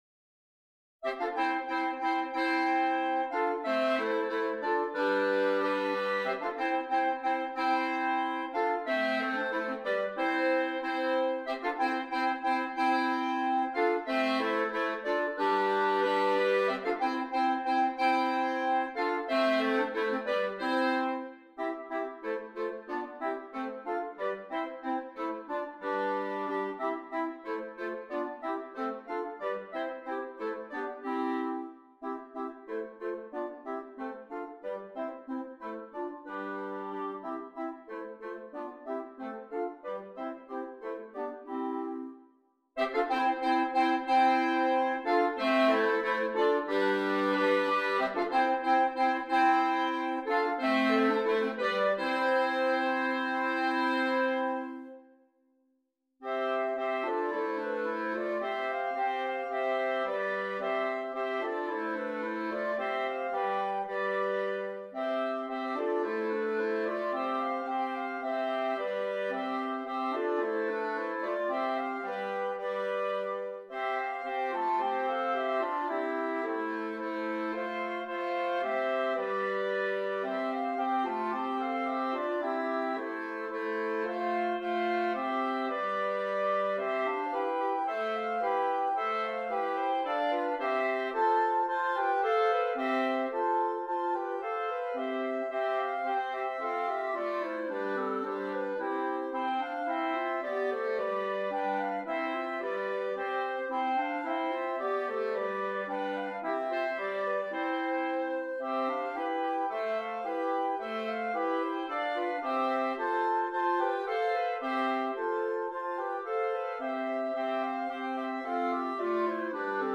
8 Clarinets